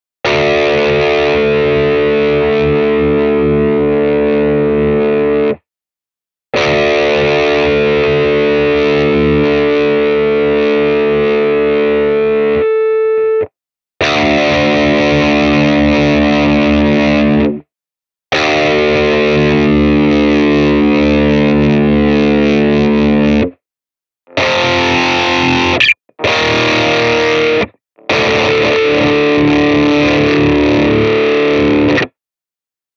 Парни, есть гитара. 2 активных хамбакера, фиксированный бридж, гриф на болтах.
У всех трёх в момент атаки открытая шестая струна высит.
Полтона на полсекунды вверх.
Дёргаю четыре раза - от слабого к сильному. Ну и три power chords в конце для полноты картины ((( Строй сейчас D, но и в E ситуация та же.